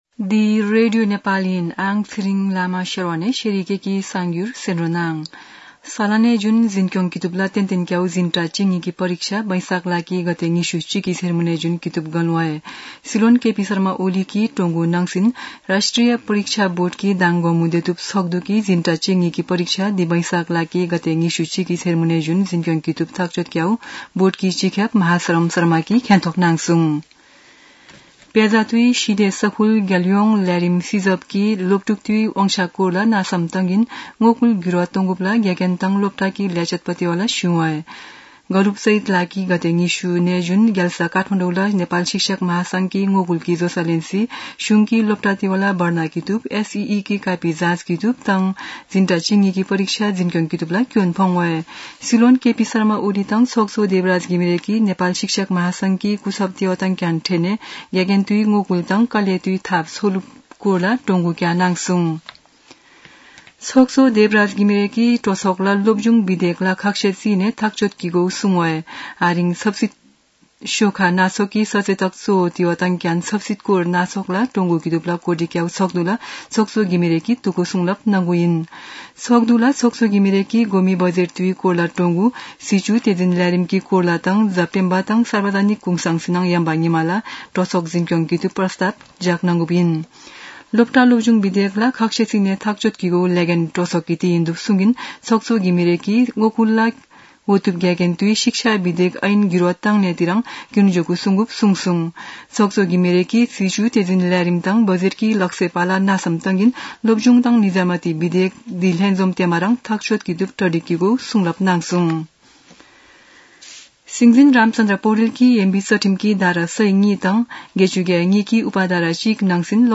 शेर्पा भाषाको समाचार : १० वैशाख , २०८२
sharpa-news-1-2.mp3